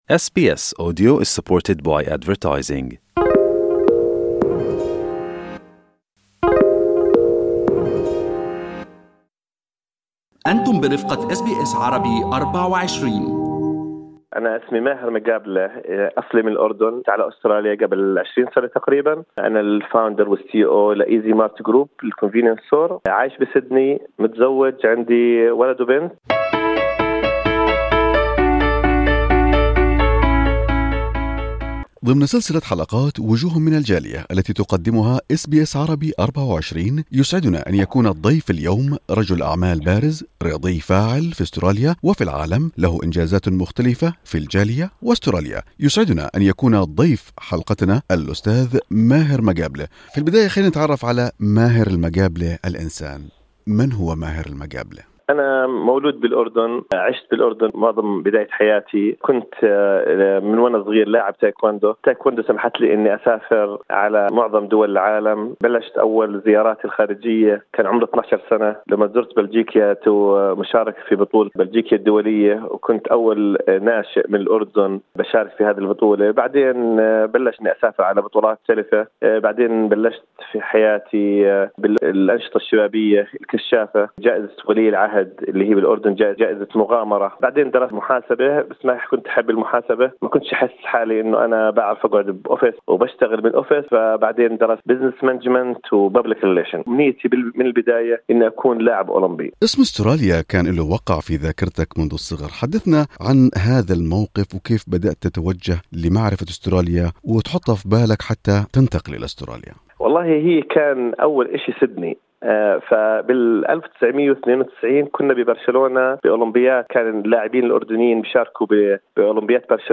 فما حكاية أولمبياد سيدني وكيف بدأ رحلة النجاح في أستراليا؟ استمعوا إلى اللقاء كاملا في المدونة الصوتية في أعلى الصفحة.